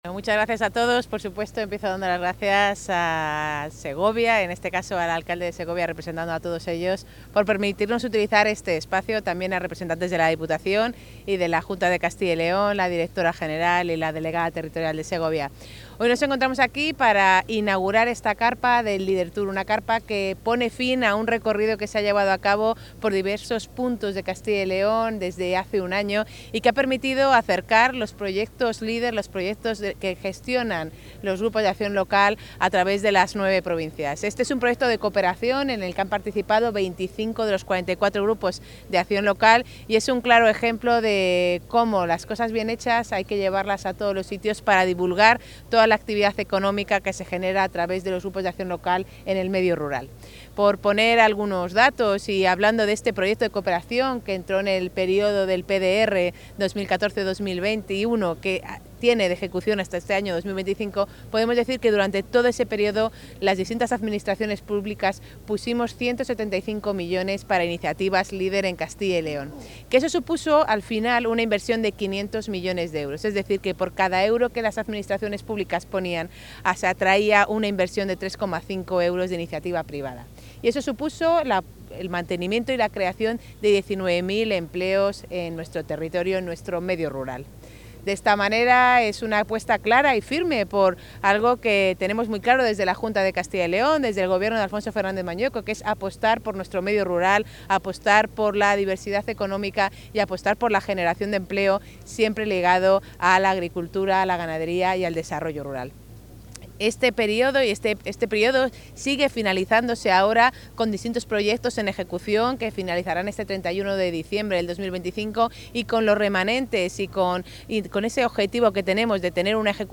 Intervención de la consejera.
La consejera de Agricultura, Ganadería y Desarrollo Rural inaugura en la Plaza Mayor de Segovia la última parada de la exposición itinerante 'LEADER Tour' que muestra los resultados del programa europeo con diferentes ejemplos de éxito, muchos de ellos relacionados con el sector agroalimentario.